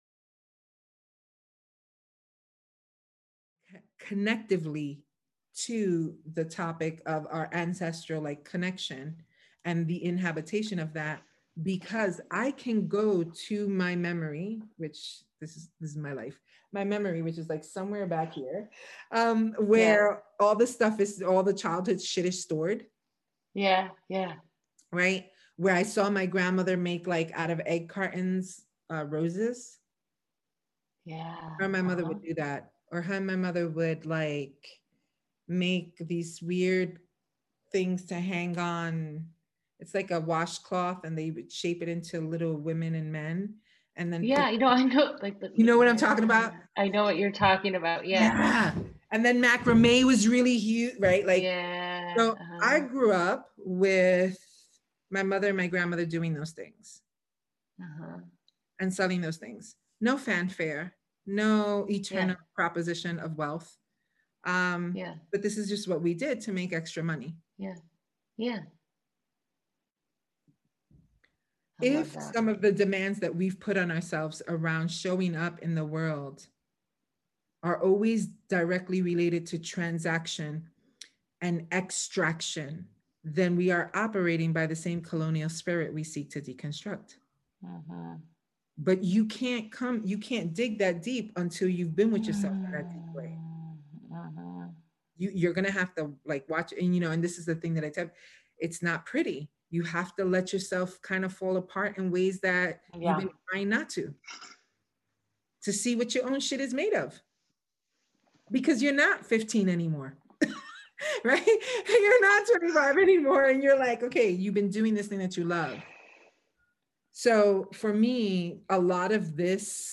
Join us in this immersive conversation that explores ancestry, work, and capitalism. Get ready to dive deep into thought-provoking discussions as we unravel the complexities of our past, challenge conventional narratives, and examine the impact of societal structures on our lives.